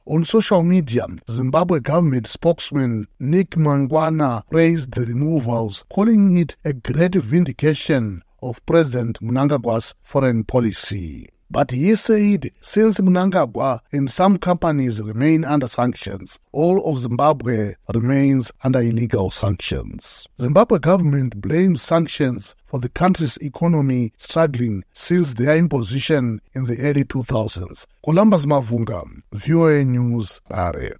reports from Harare